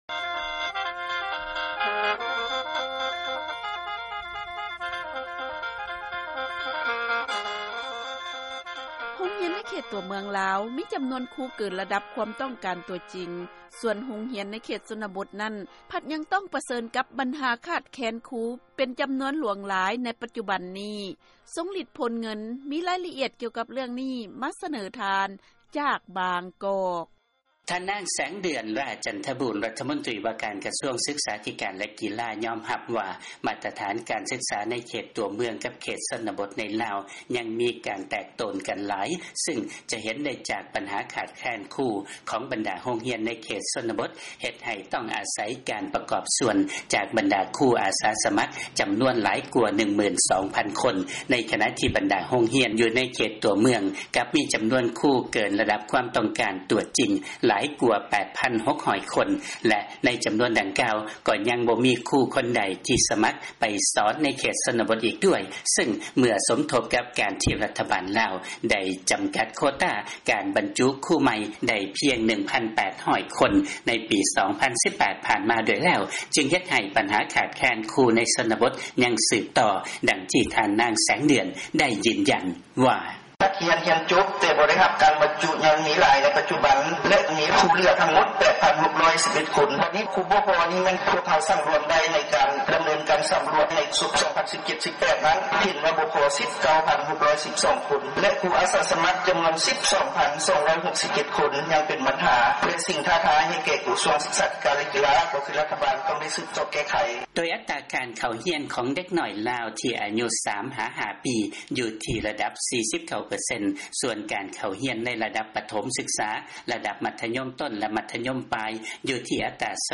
ເຊີນຟັງລາຍງານຄວາມແຕກໂຕນດ້ານການສຶກສາລະຫວ່າງເຂດຊົນນະບົດ ແລະເຂດຕົວເມືອງໃນລາວ